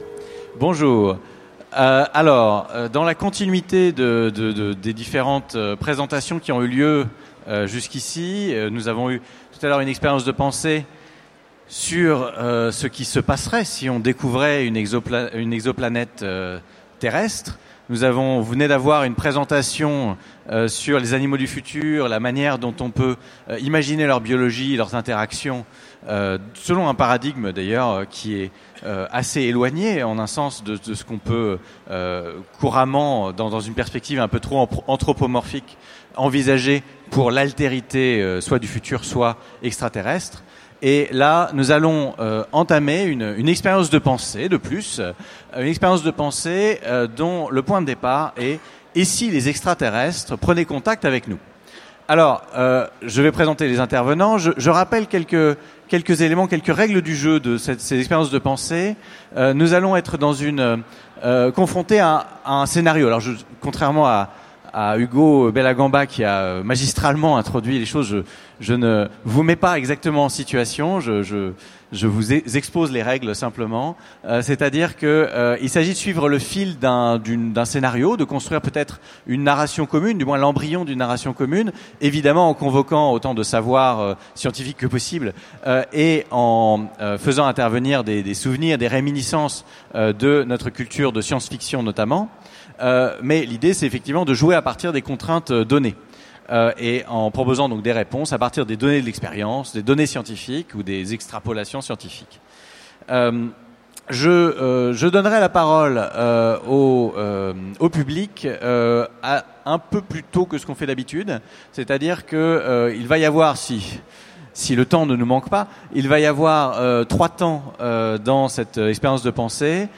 Utopiales 2015 : Conférence Les extraterrestres viennent de révéler leur présence
Conférence